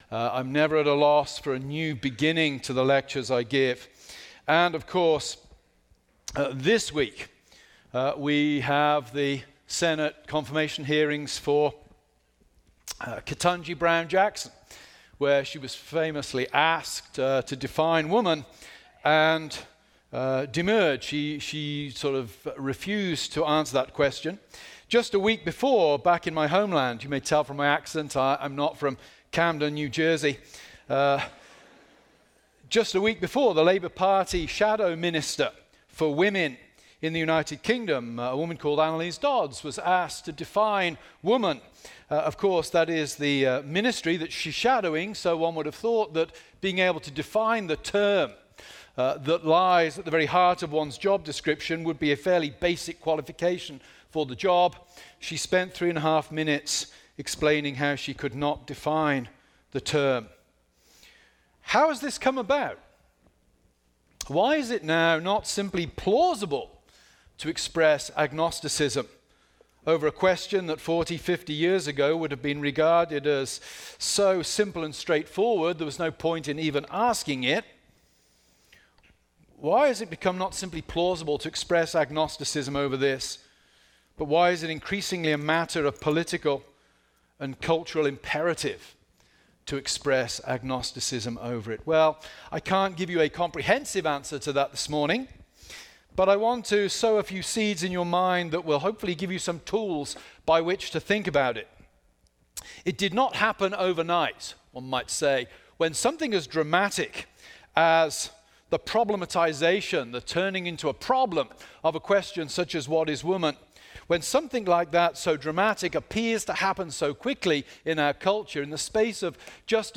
Type: Sunday School
Speaker: (video) Carl Trueman